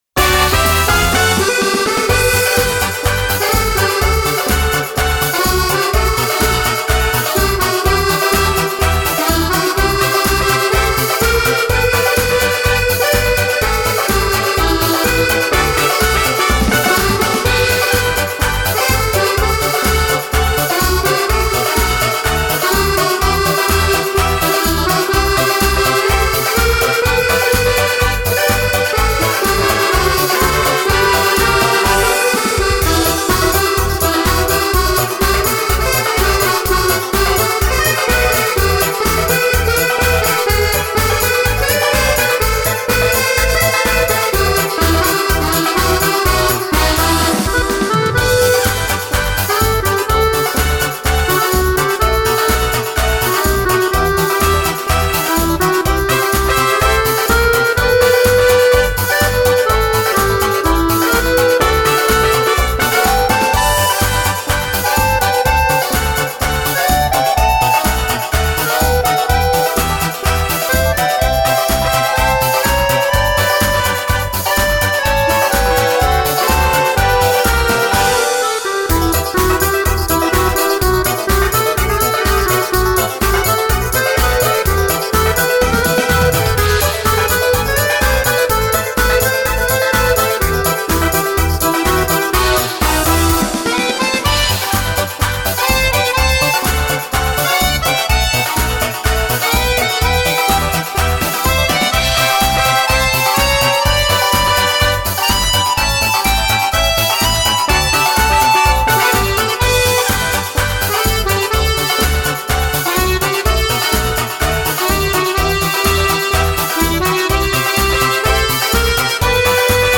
version accordéon